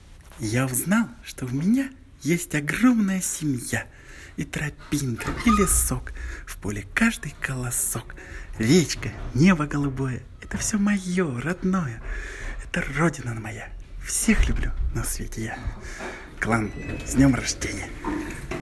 Исполнить и выложить в данной теме стихотворение детским голосом